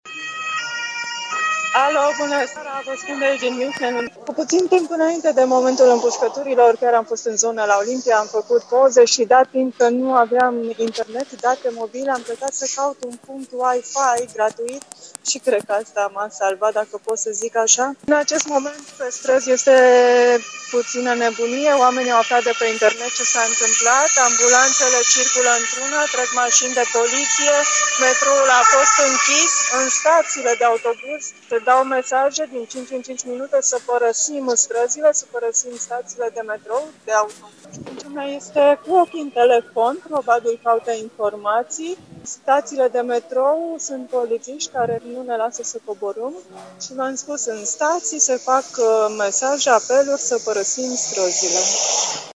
În aceste momente colega noastră se află pe stradă şi oamenii sunt speriaţi, iar autorităţile cer populaţiei să părăsească staţiile de metrou şi autobuz şi să se retragă în locuinţe.